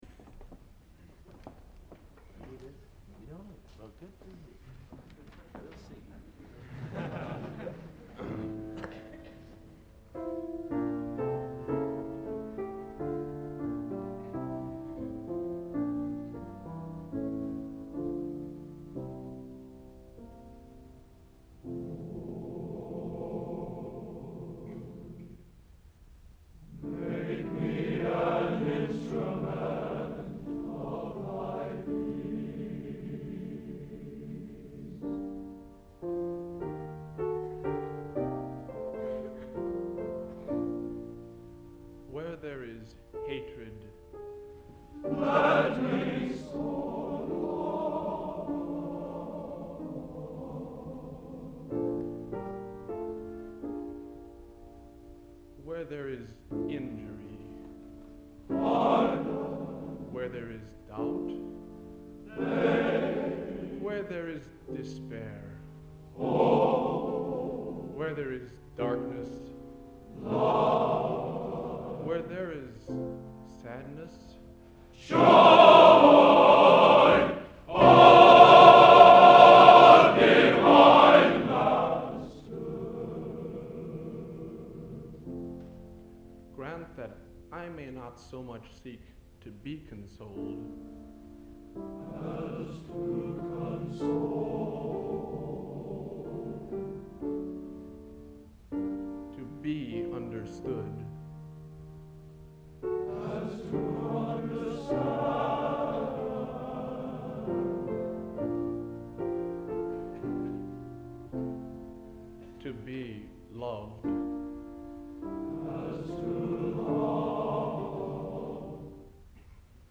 Genre: Sacred | Type: End of Season